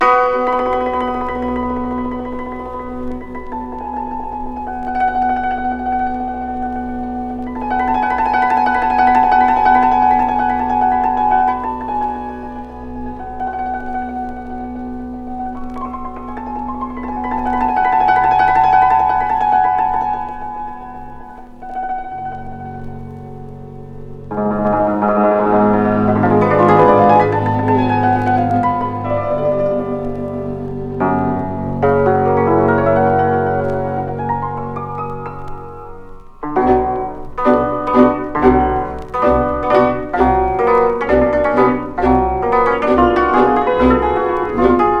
World, Folk, Romani　Hungary　12inchレコード　33rpm　Stereo